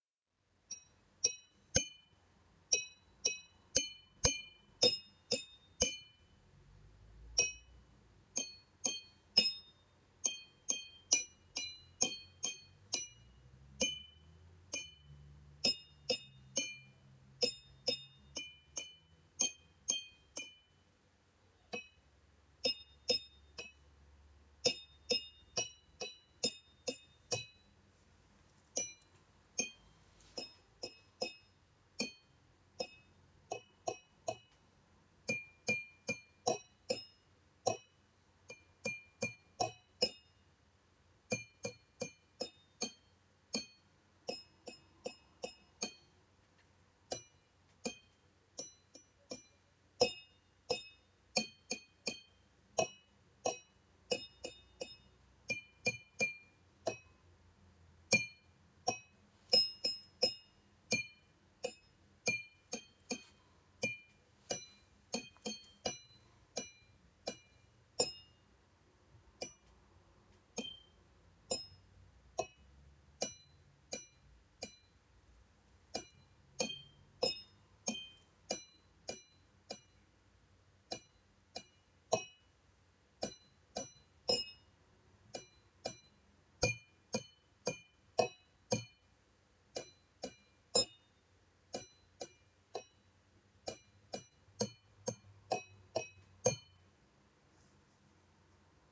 - Portal de Educación de la Junta de Castilla y León - Melodía con vasos de agua
Audio de una melodía producida con vasos de agua.